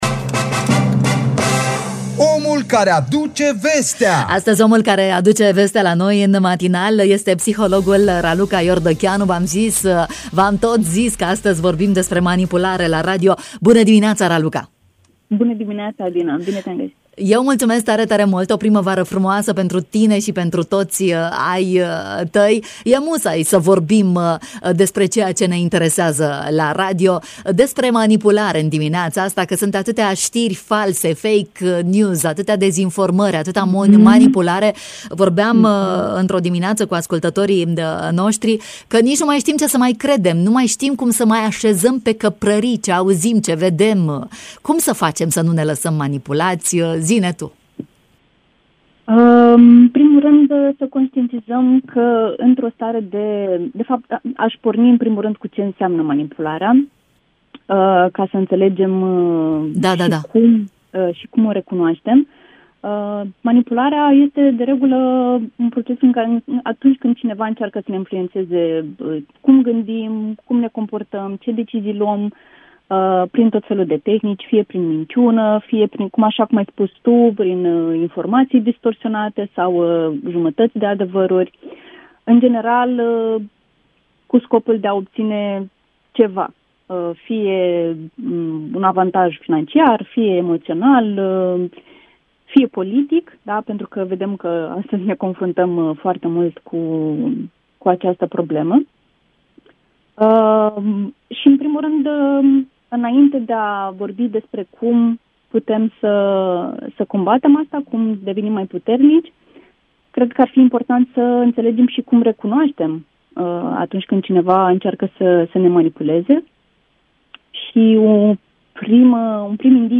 Despre manipularea psihologică și emoțională în matinalul Radio România Iași